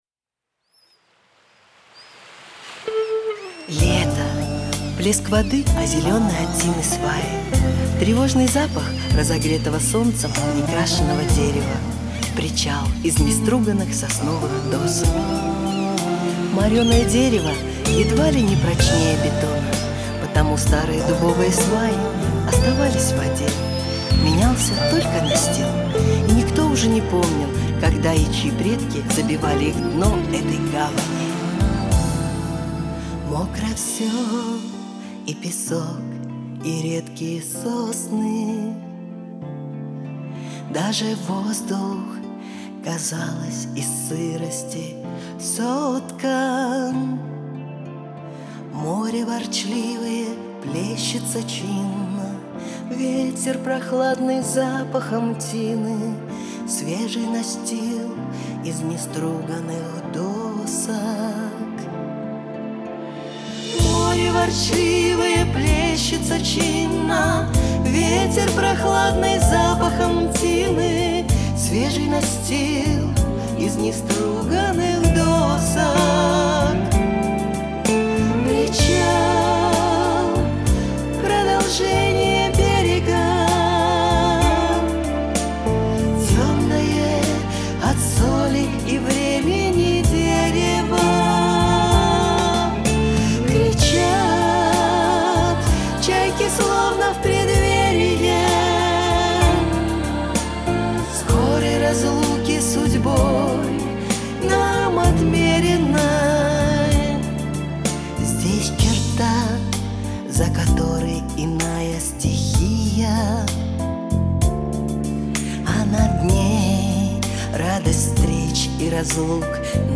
(Песенная проза)